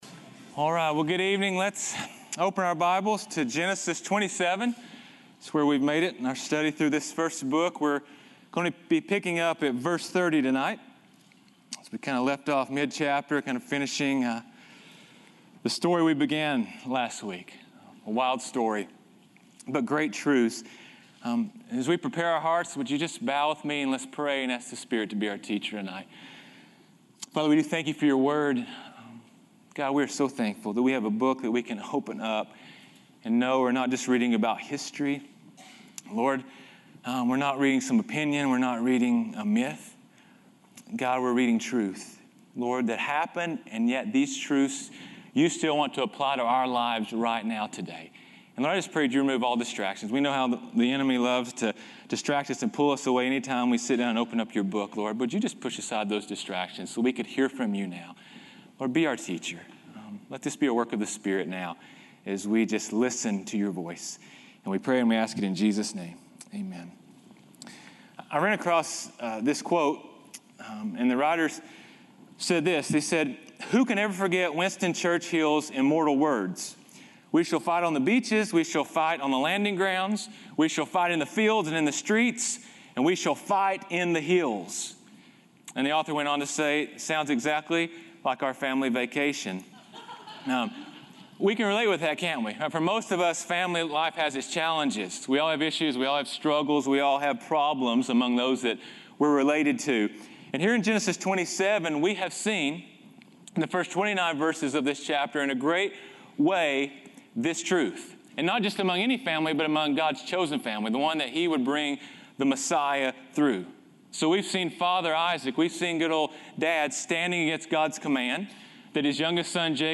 sermons
Welcome to Calvary Chapel Knoxville!